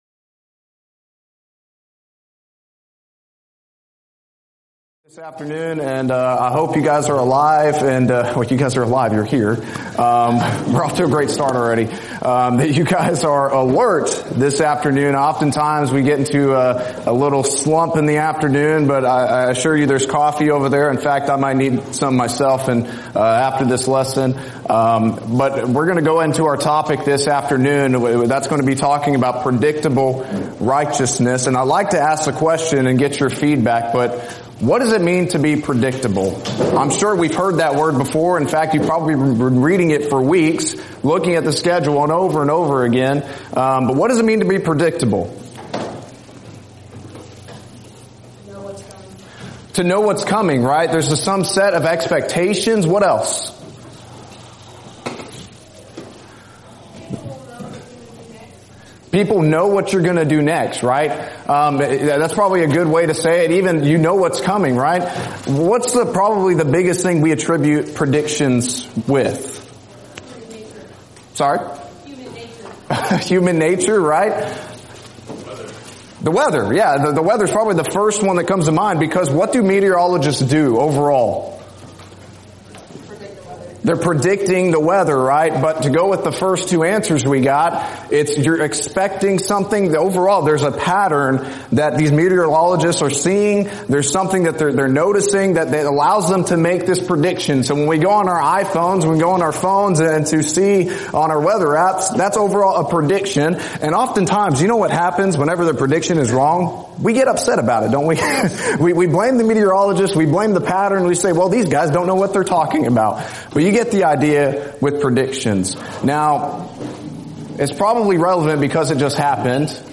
Alternate File Link File Details: Series: Arise: Southwest Spiritual Growth Workshop Event: 7th Annual Arise: Southwest Spiritual Growth Workshop Theme/Title: Arise with Conviction!
lecture